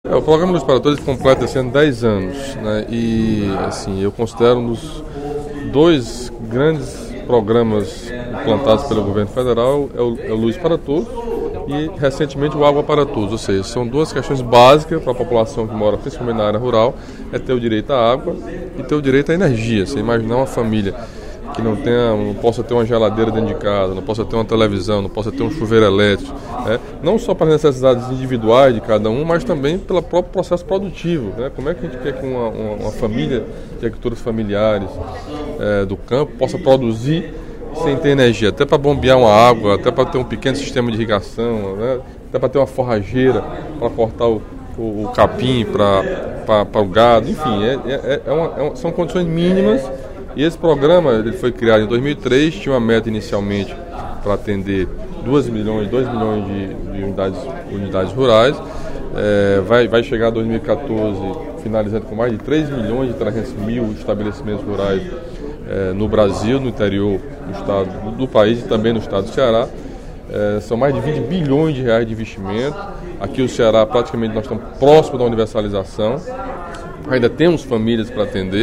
Durante o primeiro expediente da sessão plenária desta terça-feira (17/12), o deputado Camilo Santana (PT) destacou, apresentou números e as novas metas do programa do Governo Federal Luz para Todos, criado há 10 anos.